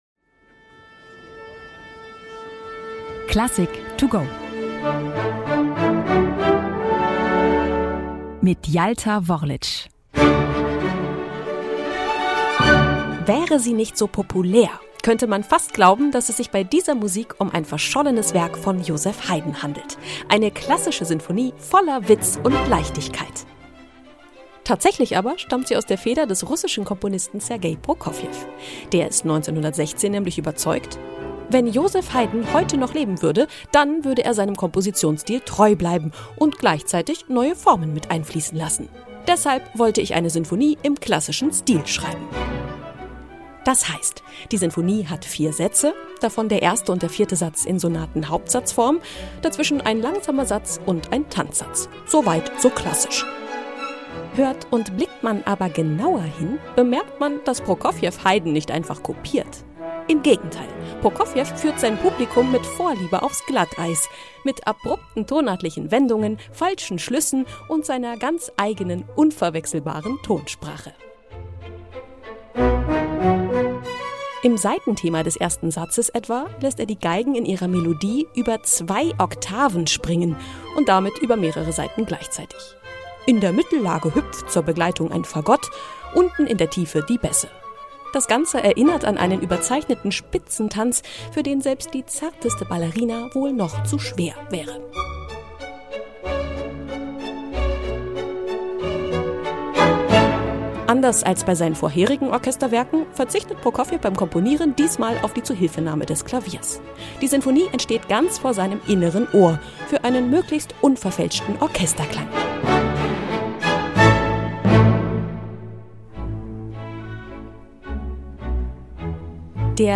"Klassik to Go" - die digitale Werkeinführung zum Download!